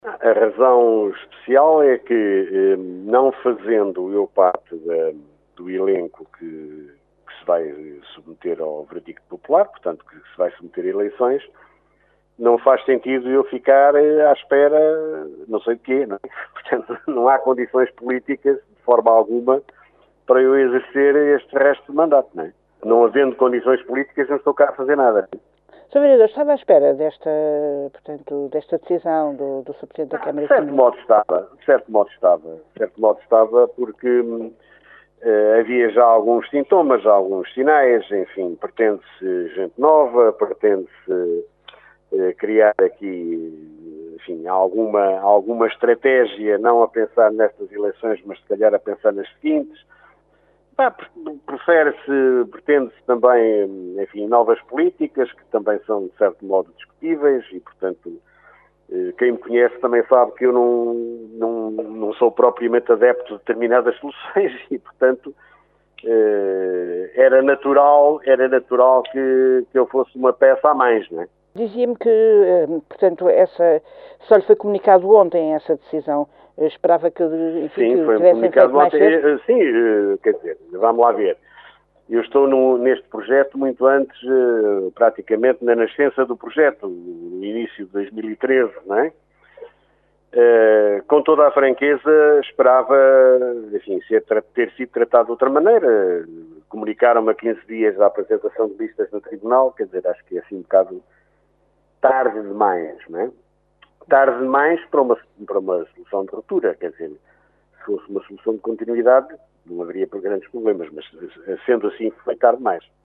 A confirmação foi feita ao Jornal C pelo próprio que, em entrevista, alegou falta de condições políticas para levar o mandato até ao fim.